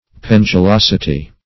Search Result for " pendulosity" : The Collaborative International Dictionary of English v.0.48: Pendulosity \Pen`du*los"i*ty\, n. [See Pendulous .]